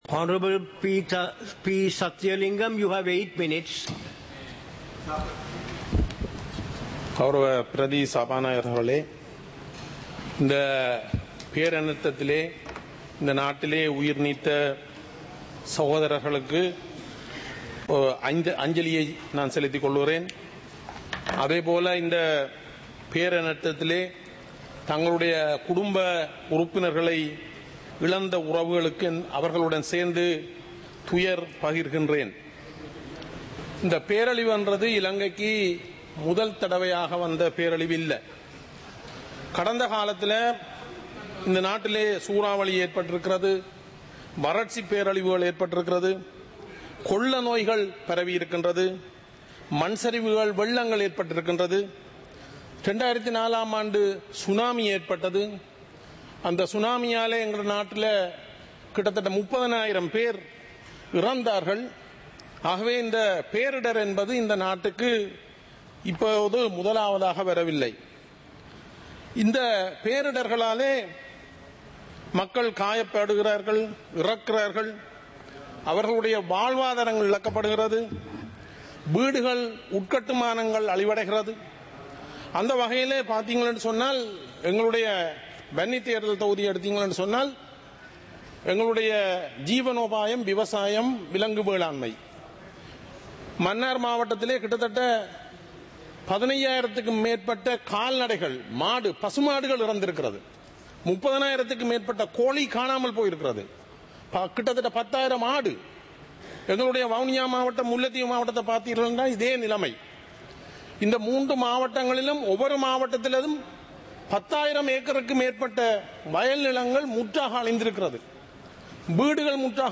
සභාවේ වැඩ කටයුතු (2025-12-19)